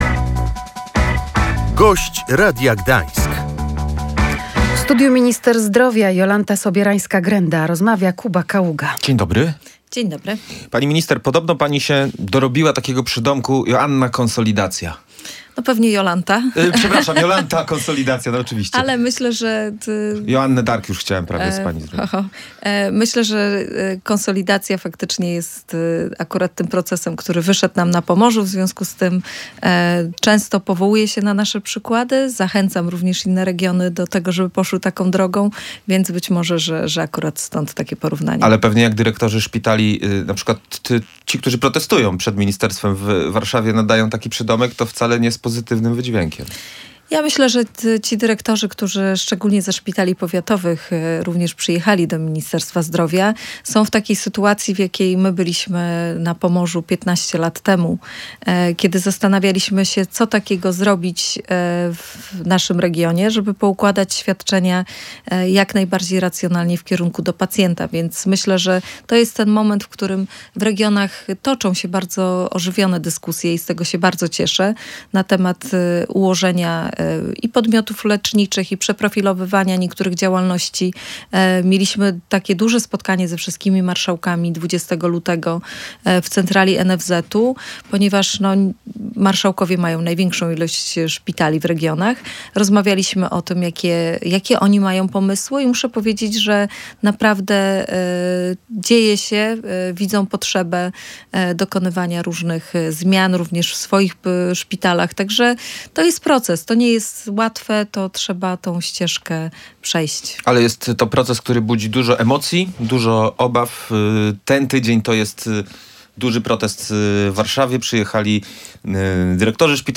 Pieniądze zostaną przekazane do szpitali za nadwykonania – mówiła w Radiu Gdańsk minister zdrowia Jolanta Sobierańska-Grenda.